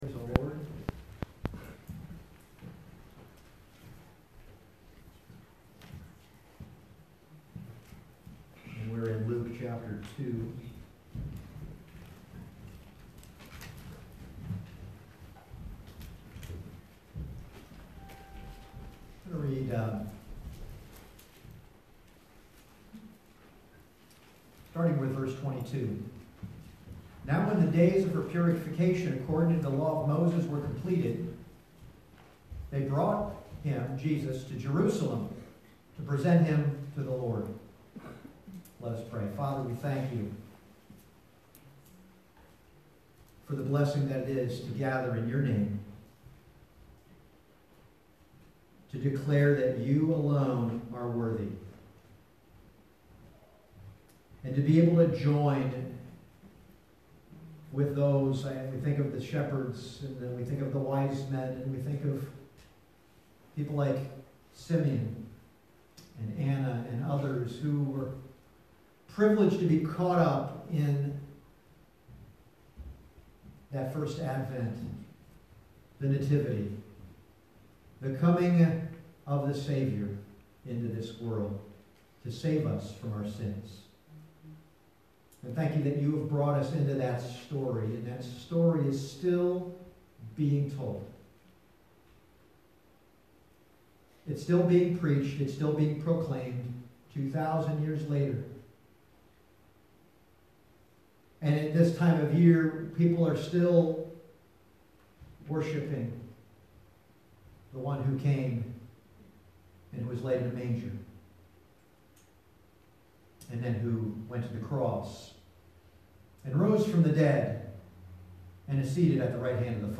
Passage: Luke 2:22-35 Service Type: Sunday Morning « Hitherto the Lord Has Helped Us Mary